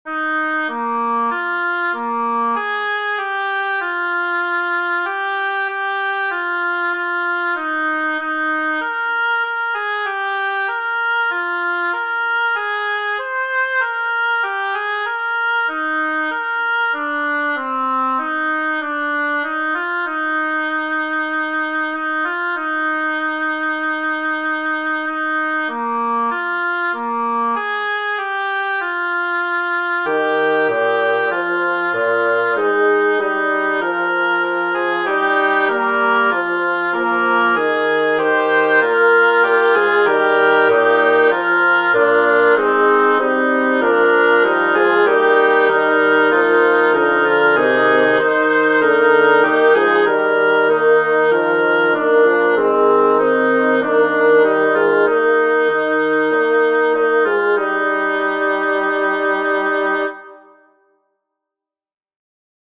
Rounds & Canons Archives - Liberty Score
It’s easy to learn and will help introduce harmony.